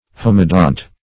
Meaning of homodont. homodont synonyms, pronunciation, spelling and more from Free Dictionary.
Search Result for " homodont" : The Collaborative International Dictionary of English v.0.48: Homodont \Hom"o*dont\, a. [Homo- + Gr.